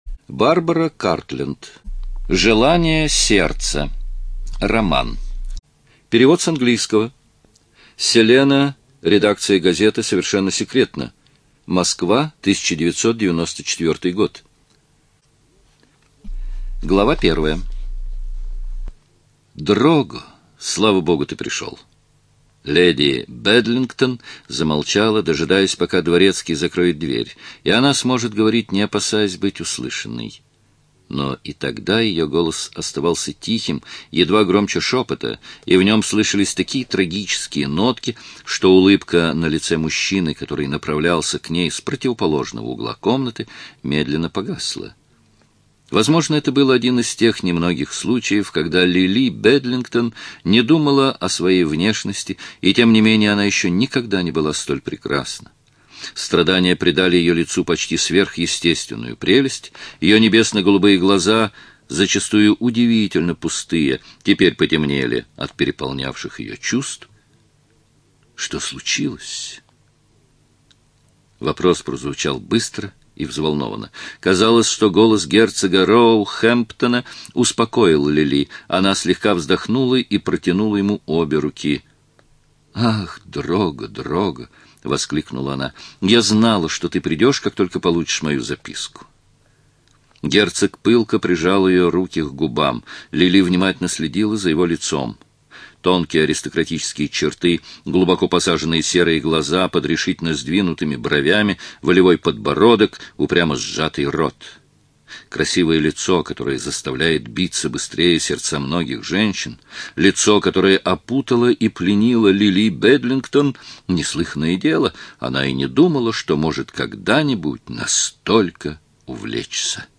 ЖанрЛюбовная проза, Историческая проза
Студия звукозаписиЛогосвос